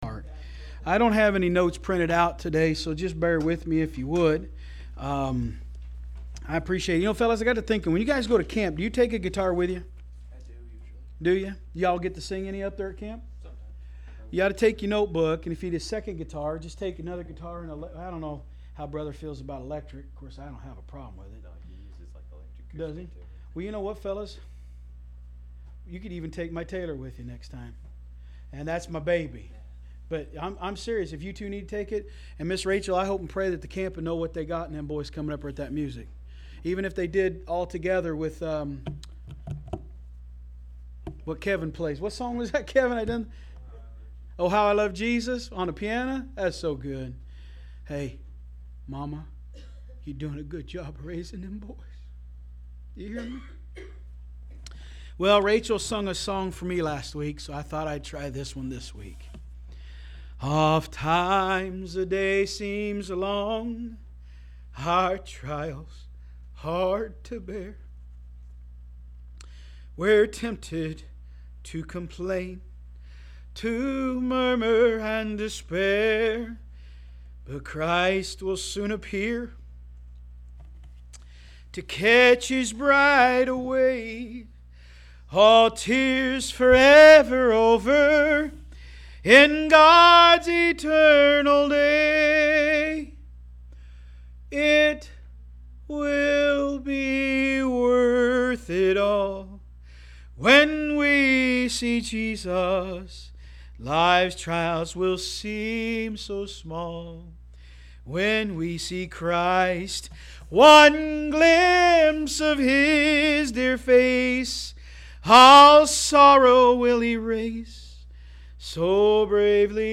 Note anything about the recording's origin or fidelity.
From Series: "AM Service"